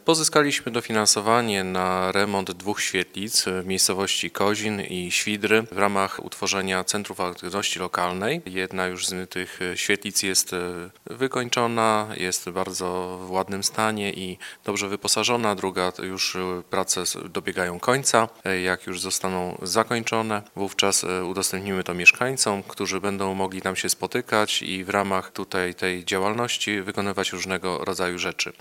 – Na stworzenie nietypowych świetlic gmina dostała dofinansowanie z unii europejskiej – mówi wójt Marek Jasudowicz.